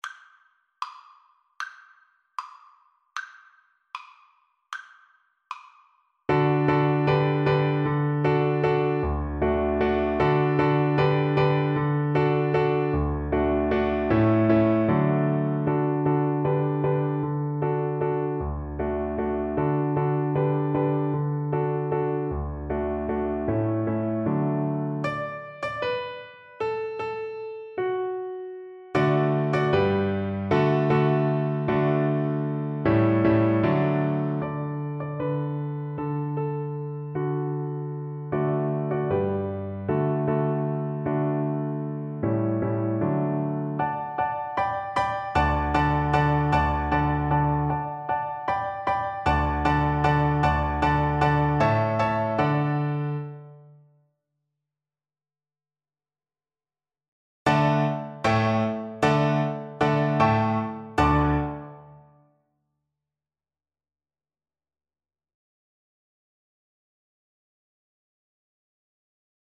Violin
Traditional Music of unknown author.
D5-E6
D major (Sounding Pitch) (View more D major Music for Violin )
4/8 (View more 4/8 Music)
Allegro Energico =240 (View more music marked Allegro)